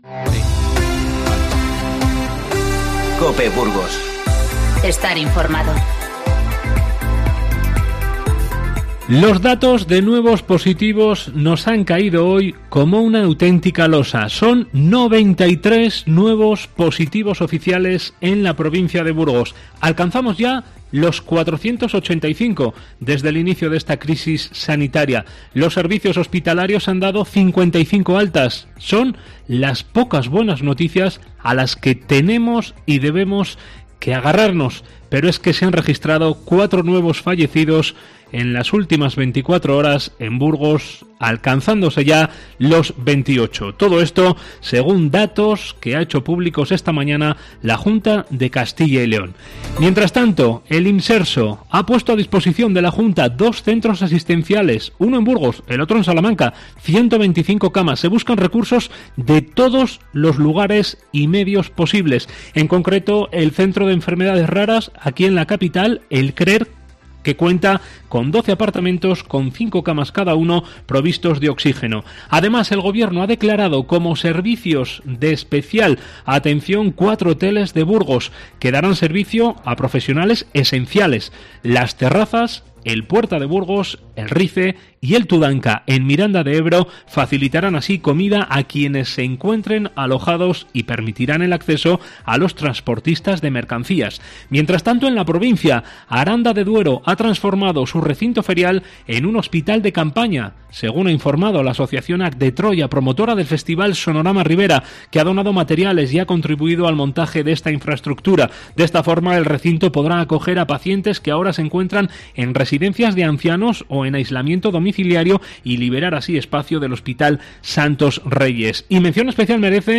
Informativo 25-03-20